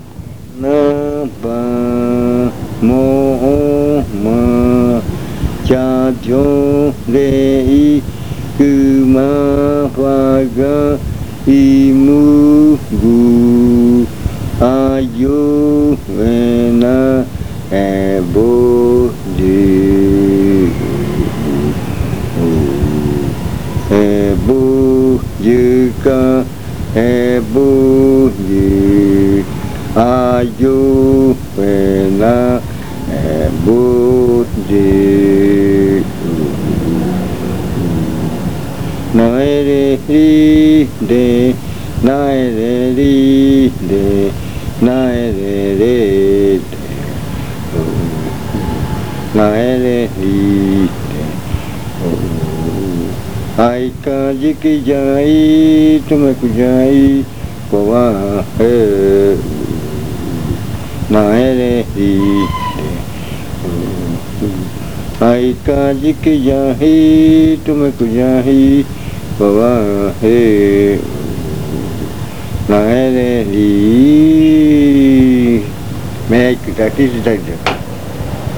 Leticia, Amazonas
Canto con adivinanza (eikɨ bɨtaja). Lo que se bota crece mucho: bien venidos.
Chant with riddle (eikɨ bɨtaja). What is thrown away grows a lot: welcome.